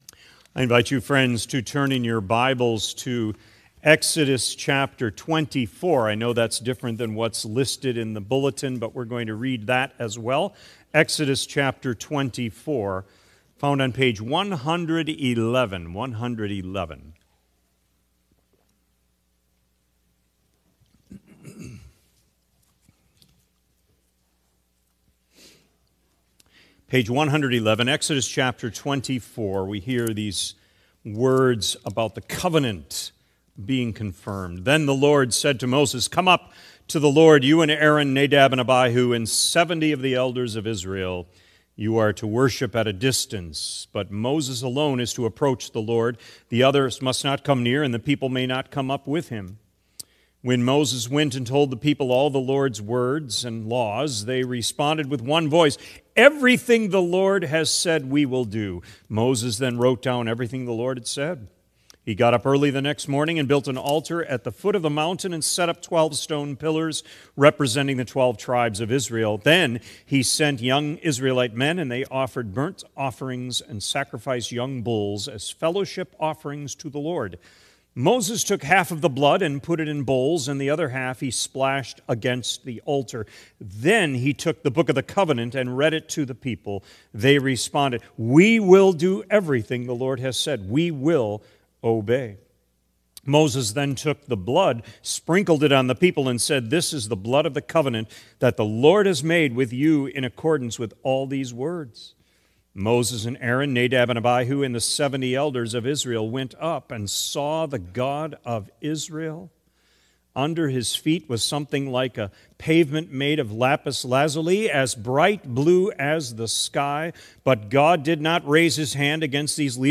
Sermon Recordings | Faith Community Christian Reformed Church
“God Seals, We Sup” November 30 2025, P.M. Service